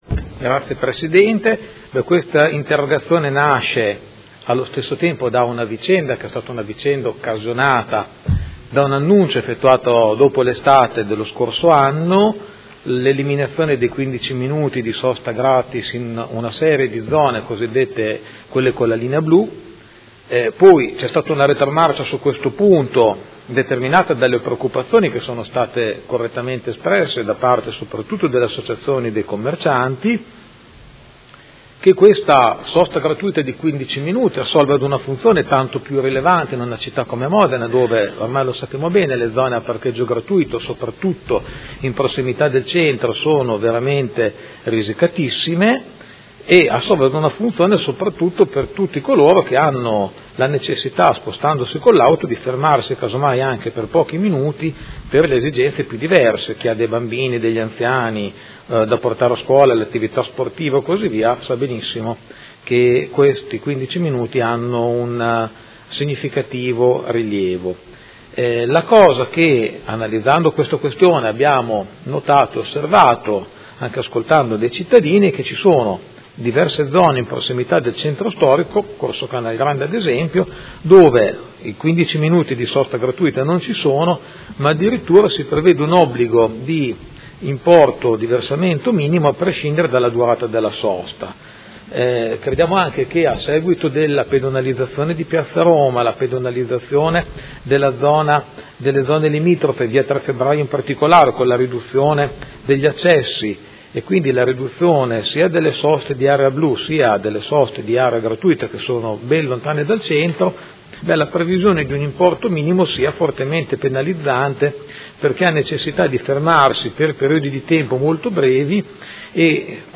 Seduta del 19/01/2017 Interrogazione del Gruppo F.I. avente per oggetto: Importi minimi per la sosta in alcune zone della città